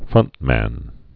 (frŭntmăn)